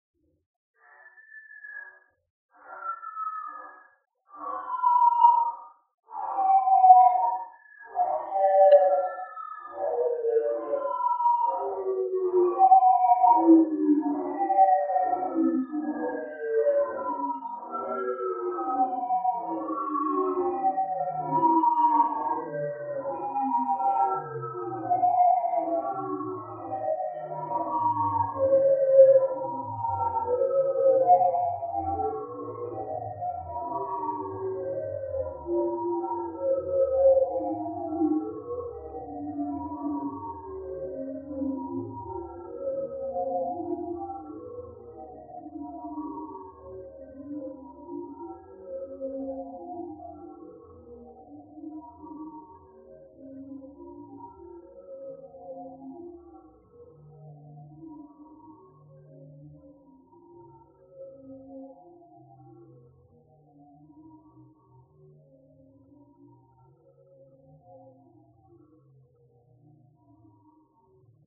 Tag: 警报 环境 无人机 实验 噪音 警告